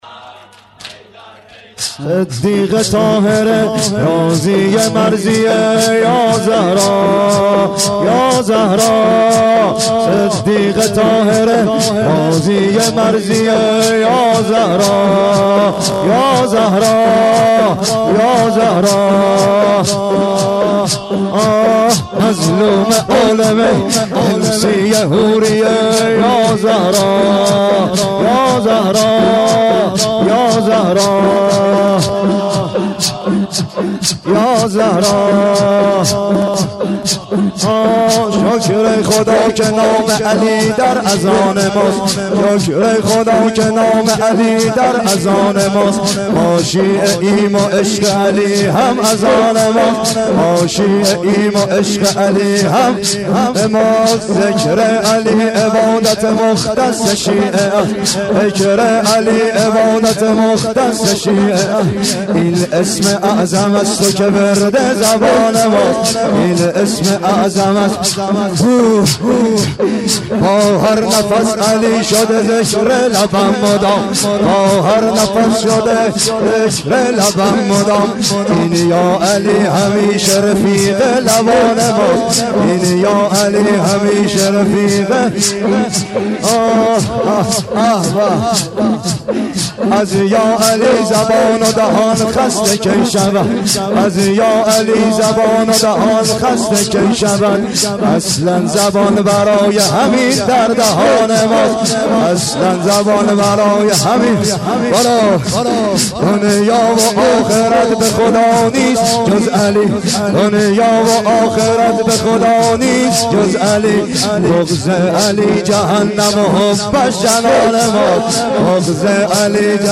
صدیقه طاهره ، راضیه مرضیه(سینه زنی/تک